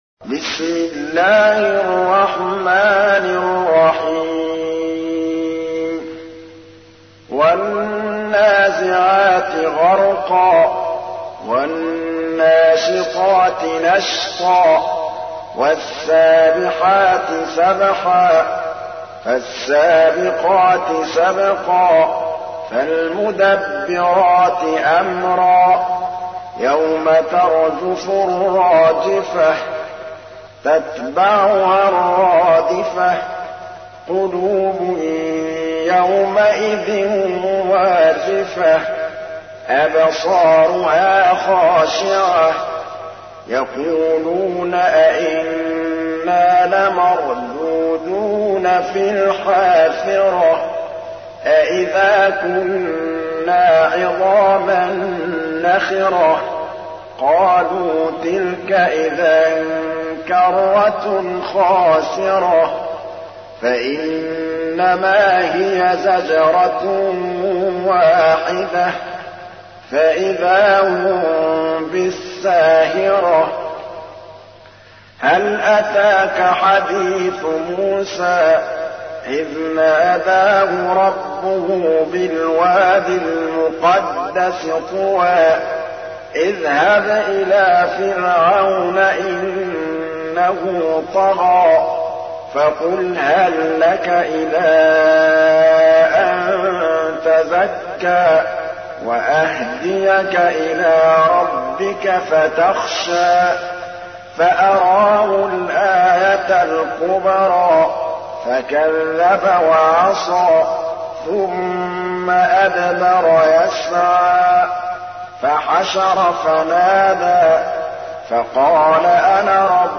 تحميل : 79. سورة النازعات / القارئ محمود الطبلاوي / القرآن الكريم / موقع يا حسين